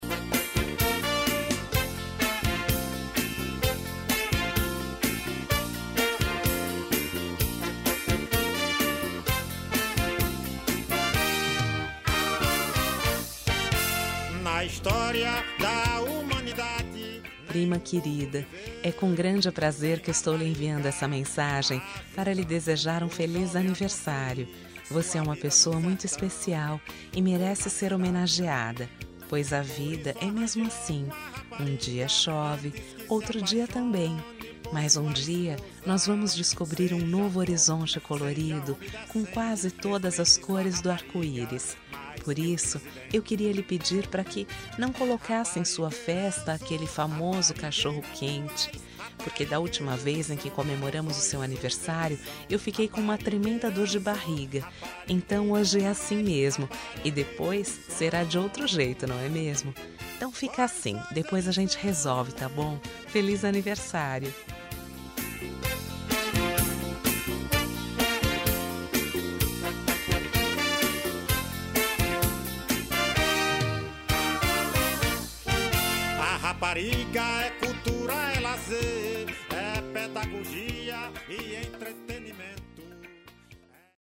Aniversário de Humor – Voz Feminina – Cód: 200111